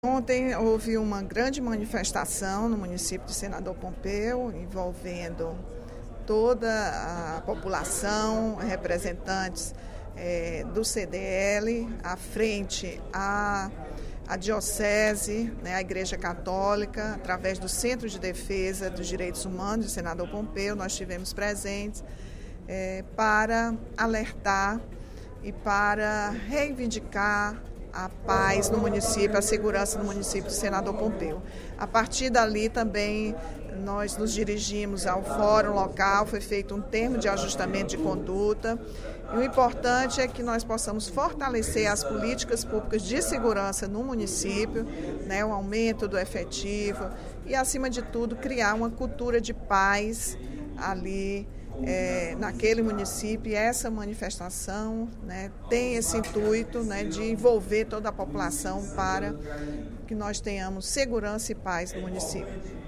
Durante o primeiro expediente da sessão plenária desta quinta-feira (27/02), a deputada Rachel Marques (PT) ressaltou visita feita ontem ao município de Senador Pompeu, a 275 quilômetros de Fortaleza.
Em aparte, os deputados Camilo Santana (PT) e Inês Arruda (PMDB) mostraram preocupação com a insegurança na região.